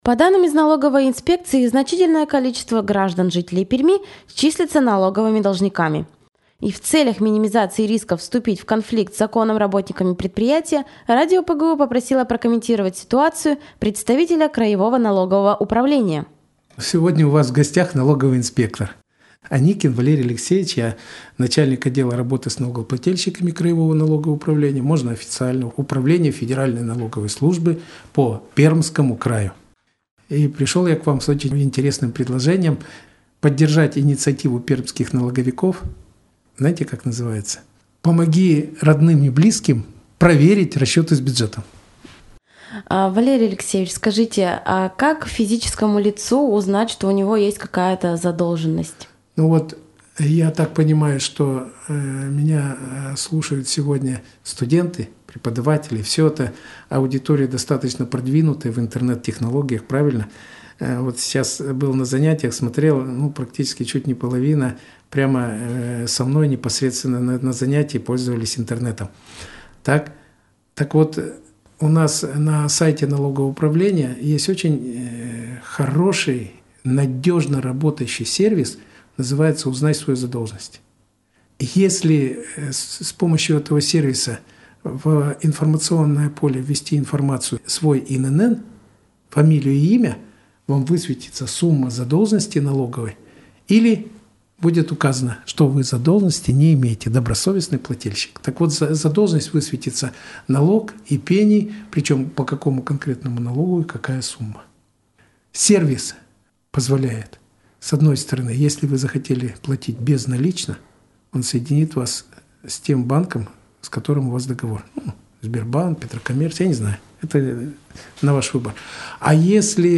интервью.
interview.mp3